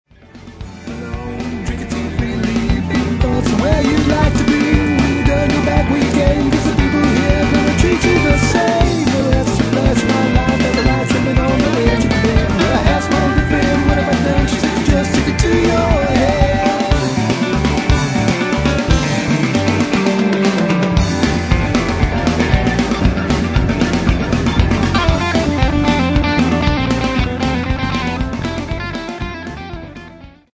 Experimental Rock Three Piece
- compositions, vocals, guitar
- drums
- bass, keyboards, guitar
recorded at Midtown Recording Studio